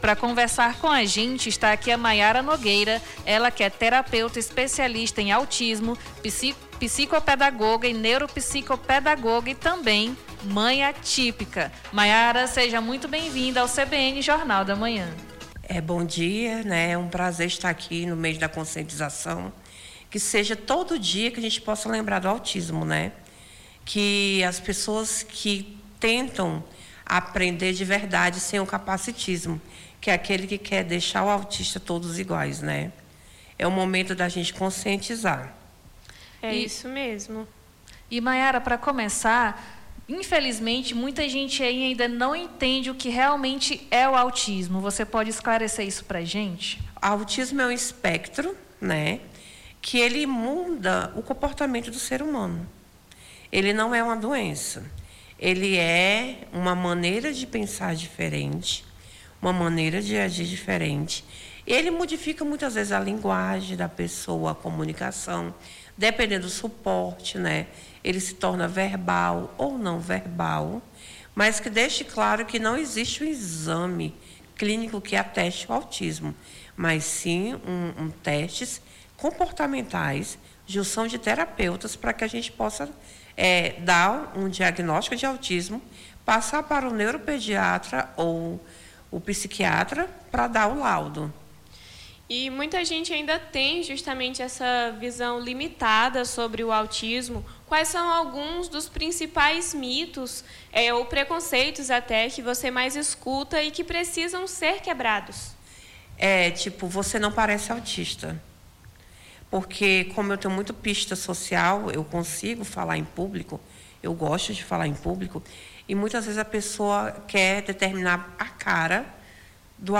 ENTREVISTA MES CONSCIENTIZACAO AUTISMO - 06-04-26.mp3 Digite seu texto aqui...